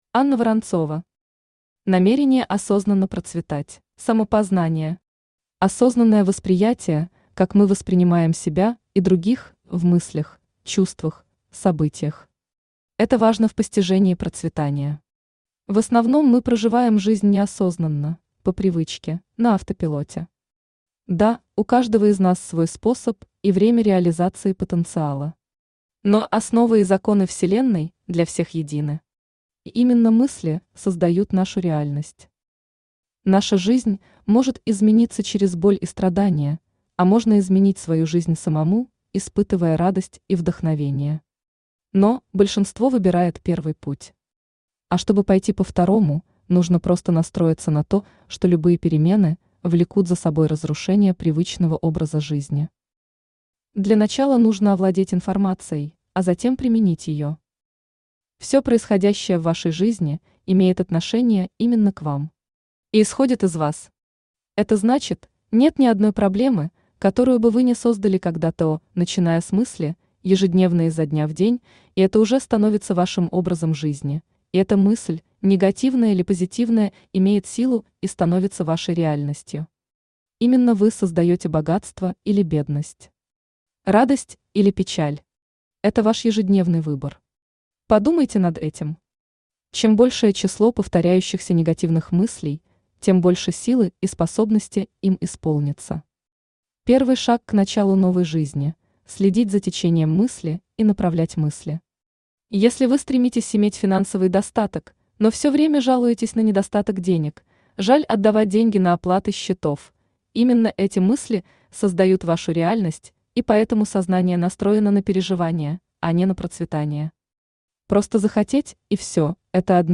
Аудиокнига Намерение Осознанно Процветать | Библиотека аудиокниг